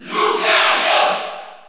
Category: Crowd cheers (SSBU) You cannot overwrite this file.
Lucario_Cheer_French_PAL_SSBU.ogg.mp3